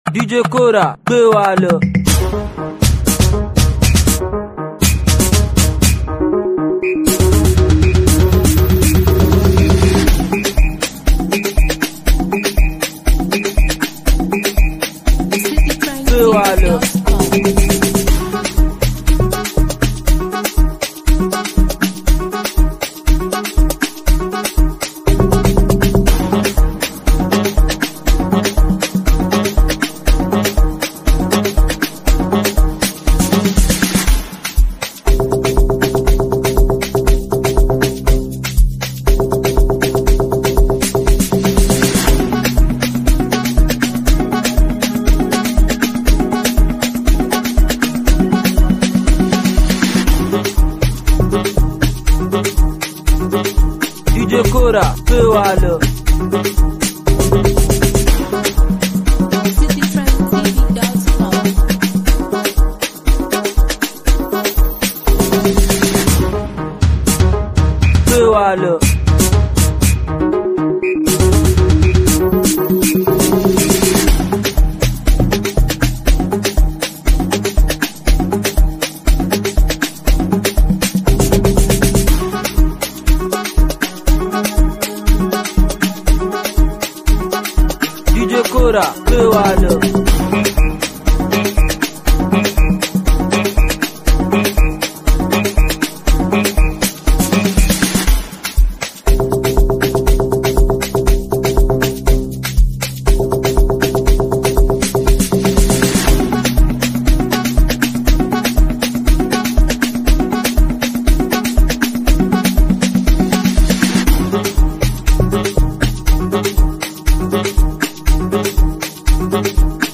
feel-good energy and irresistible rhythm